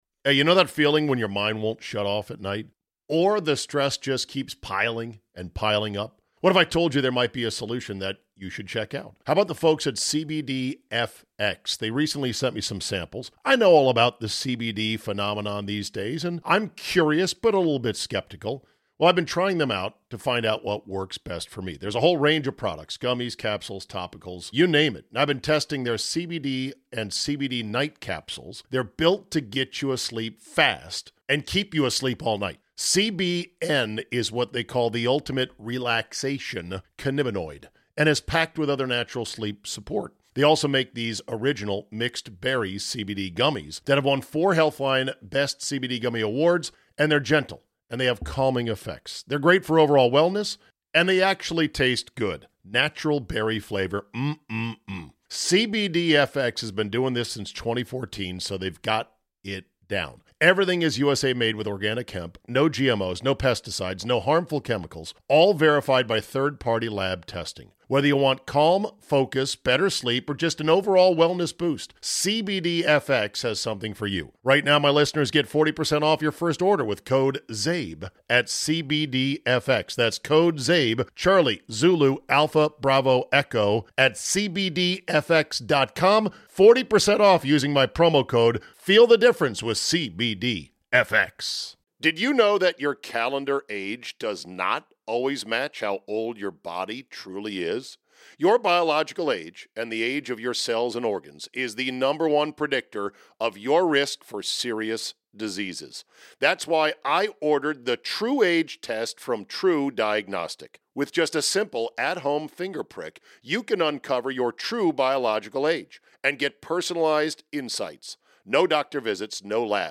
the Palm in Tysons for a Power Lunch